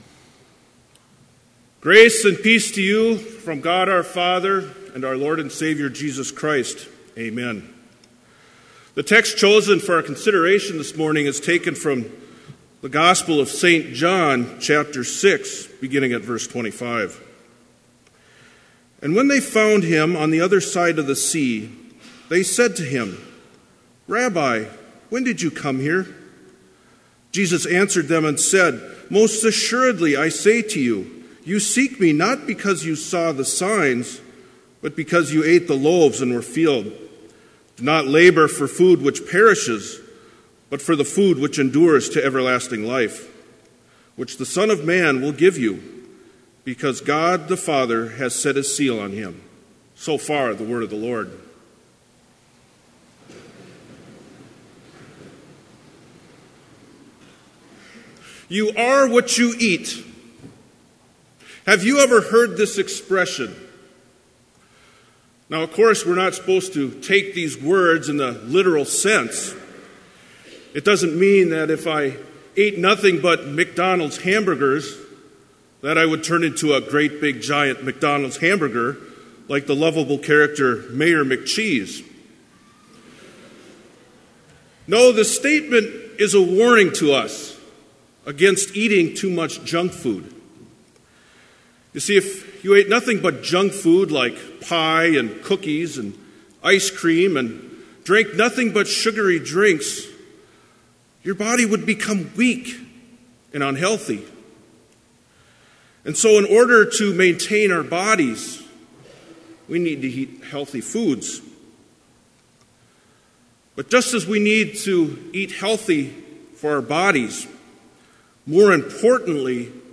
Complete service audio for Chapel - March 30, 2017